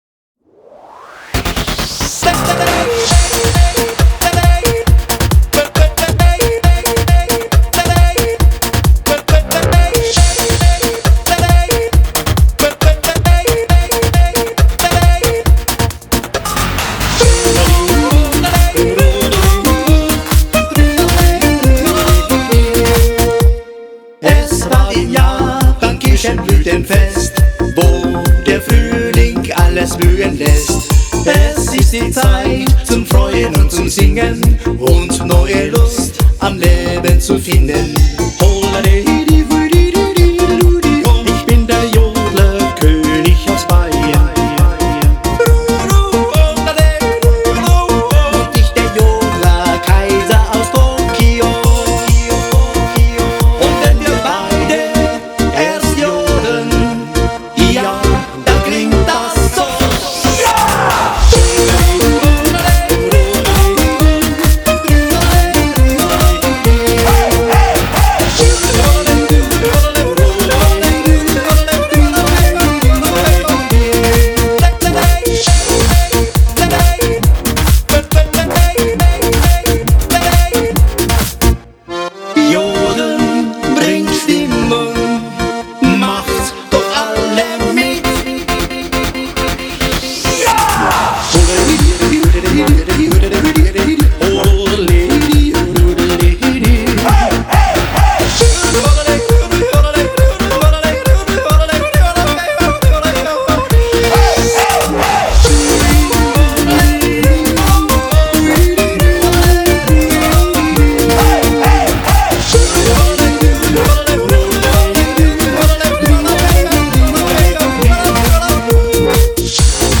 BPM136
Comments[YODEL DUET EURODANCE]